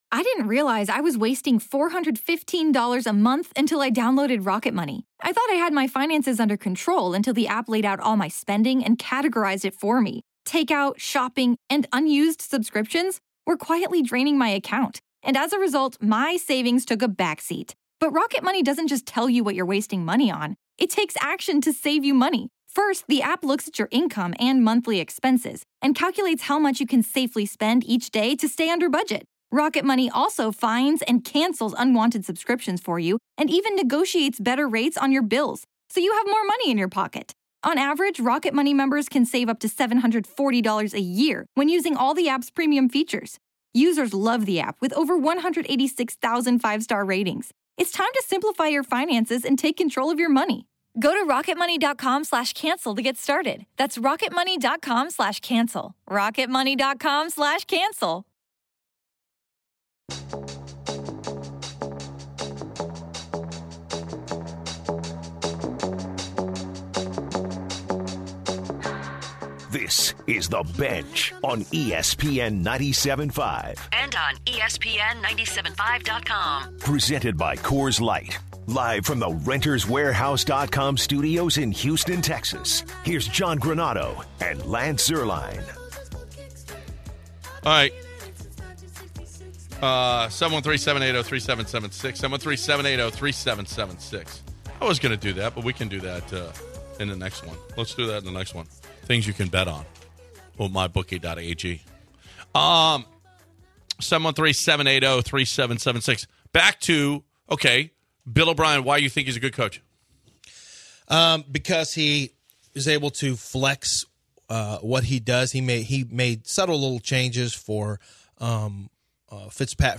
In the second and final hour of the show, the Texans talk continues as they discuss Bill O’Brien’s performance as Head Coach, compare the team to their division rivals, and look at the roster top to bottom. At the bottom of the hour, some of their coworkers from CultureMap join the guys in the studio to talk about a number of Houston’s best up and coming restaurants. They finish out the show comparing the accomplishments of Jeff Luhnow and Daryl Morey.